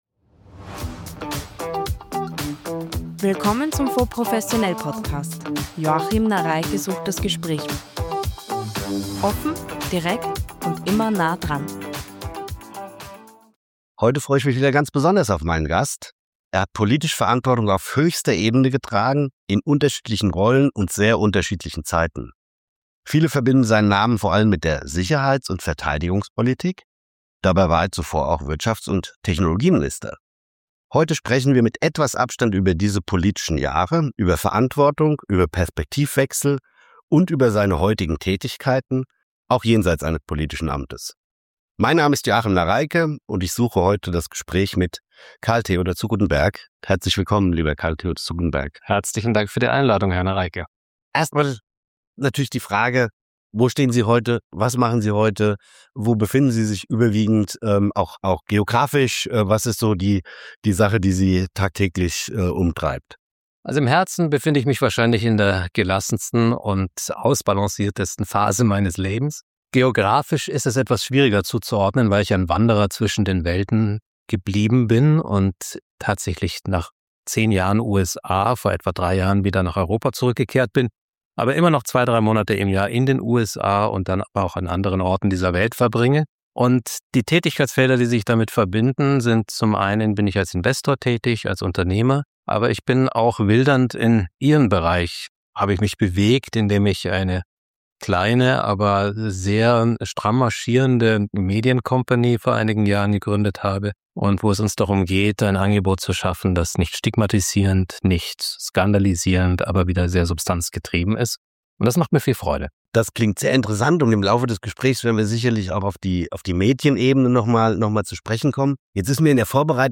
Ex-Bundesminister Karl-Theodor zu Guttenberg blickt im aktuellen FONDS professionell Podcast auf politische Verantwortung, Scheitern als Lernprozess und den Mut zum Risiko. Ein Gespräch über Investitionen, Mindsets, Medien, Gesellschaft – und warum Europa jetzt umdenken muss.